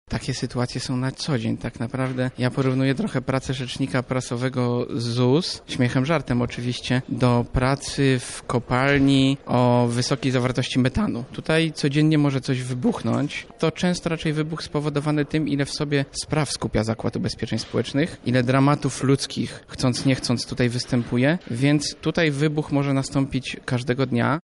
W Chatce Żaka trwa panel ekspercki poświęcony przedstawicielom tego zawodu.